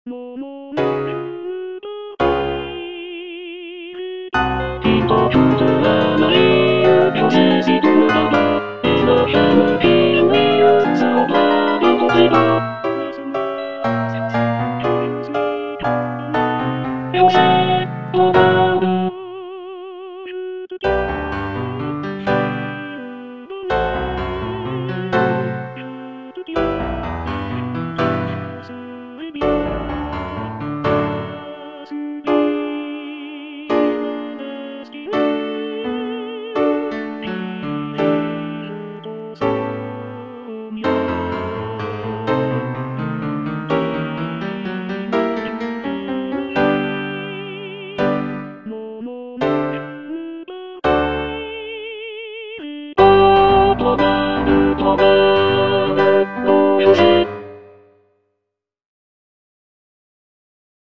G. BIZET - CHOIRS FROM "CARMEN" Il t'en coutera la vie - Tenor (Emphasised voice and other voices) Ads stop: auto-stop